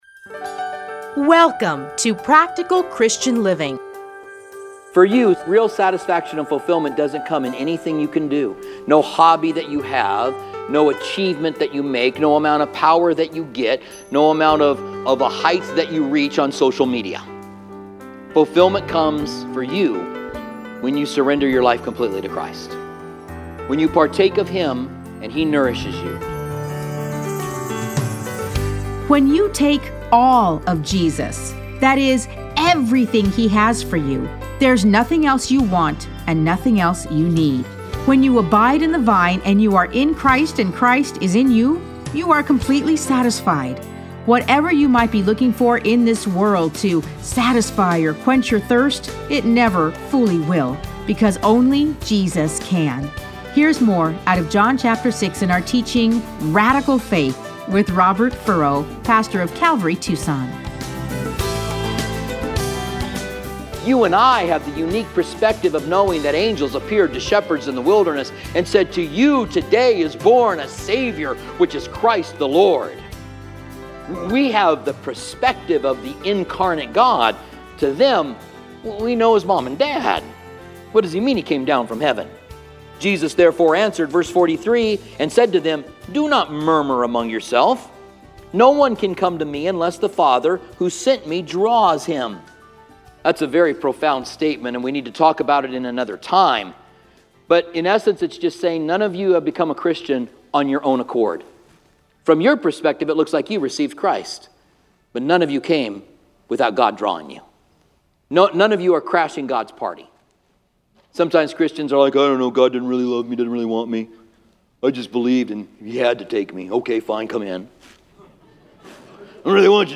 Listen to a teaching from John 6:41-71.